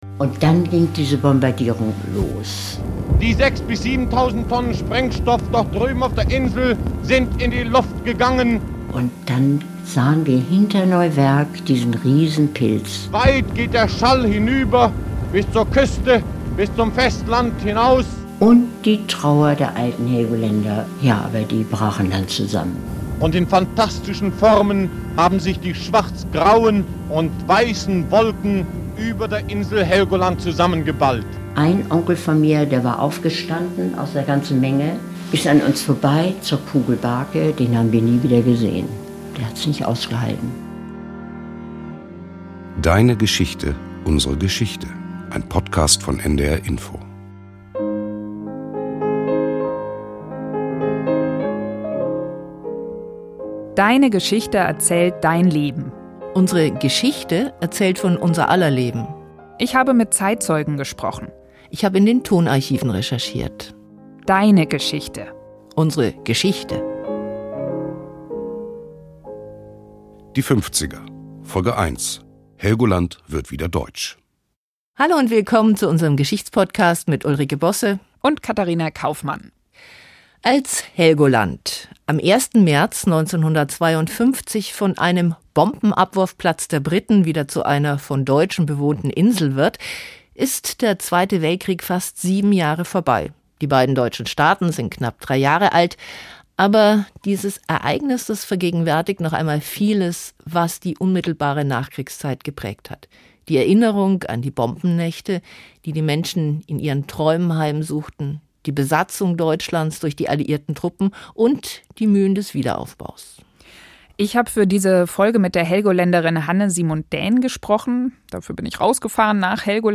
Eine Helgoländerin erinnert sich, wie sie das alles als Kind und junge Frau miterlebt hat; historische Reportagen lassen die dramatischen Ereignisse noch einmal gegenwärtig werden.